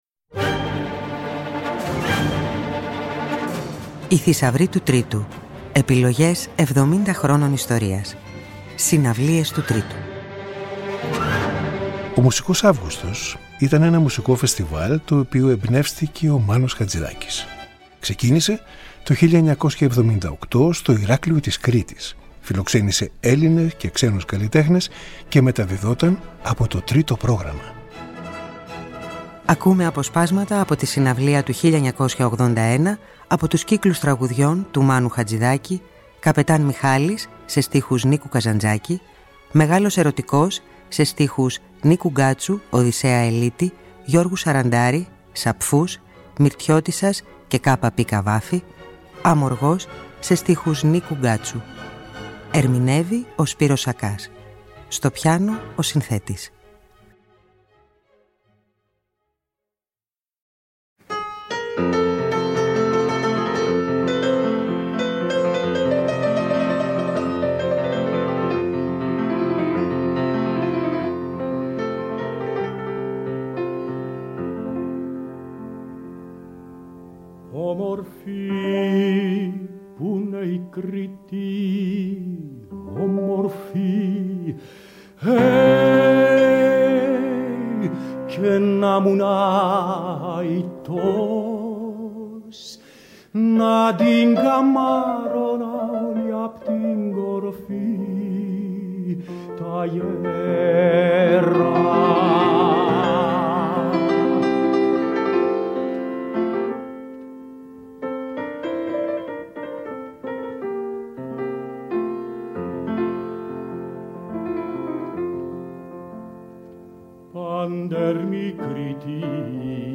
θα μεταδοθεί ένα απόσπασμα από τη συναυλία
που δόθηκε στο Μικρό Θέατρο Κήπου στο Ηράκλειο της Κρήτης.